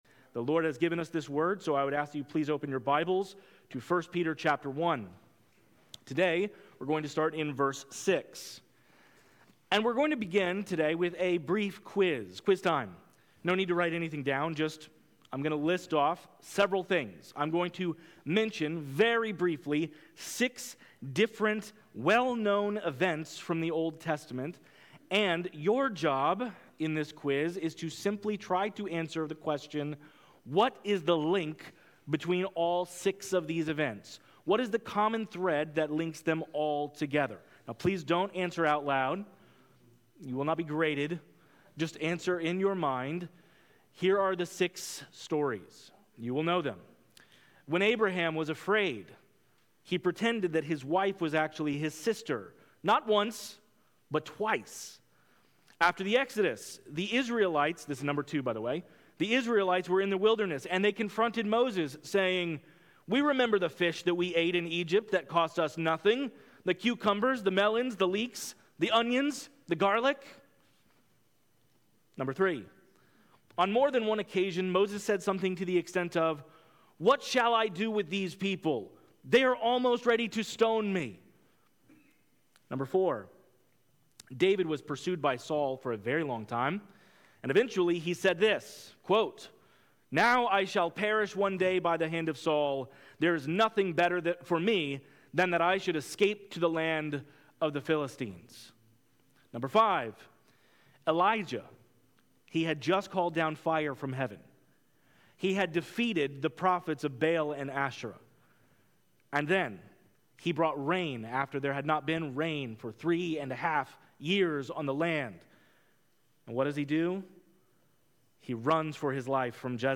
This podcast contains the sermons preached at Levittown Baptist Church in Levittown, New York.